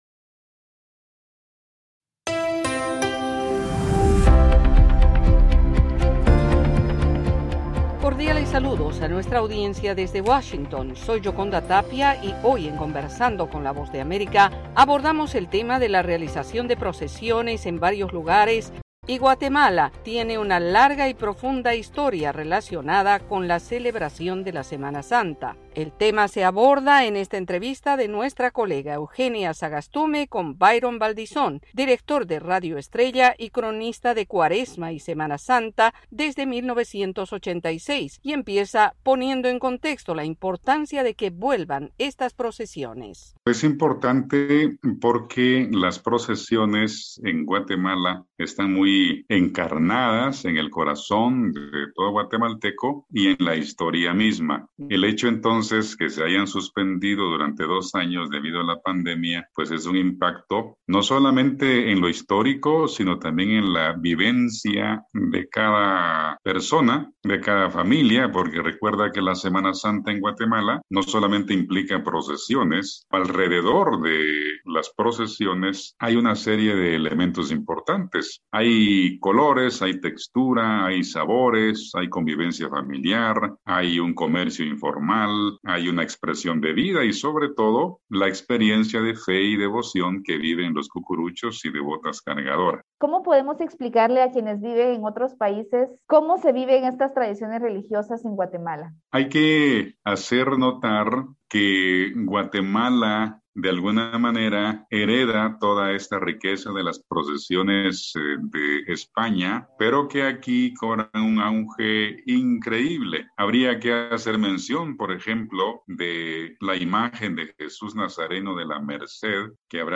Conversando con la VOA